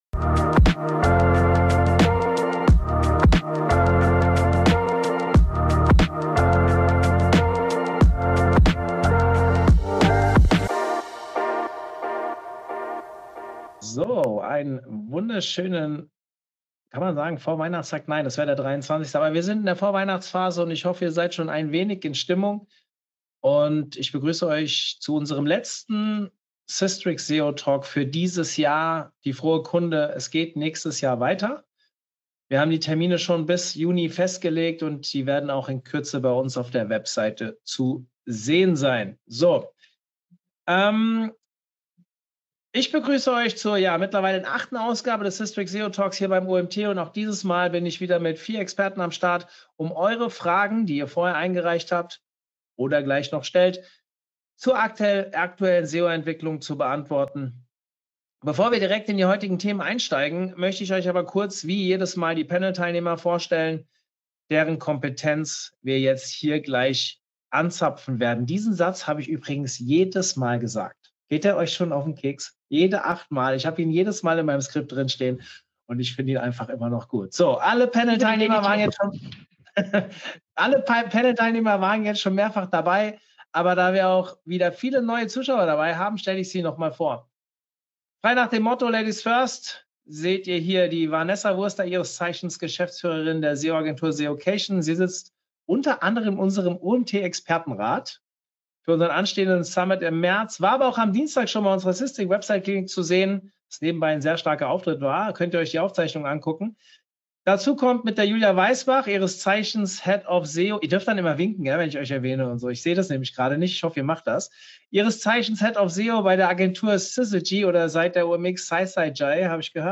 SISTRIX SEO Talk: Runde 8 (Aufzeichnung)